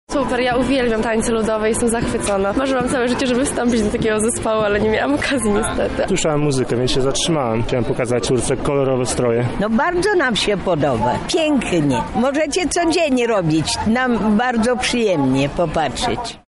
W ramach dnia tańca ludowego, na Placu Litewskim w Lublinie zaprezentowały się w niedzielę ludowe zespoły, które dla widzów nie tylko zatańczyły, ale zachęcały do udziału w tradycyjnych zabawach.
Co o swojej pasji mówią osoby należące do zespołów?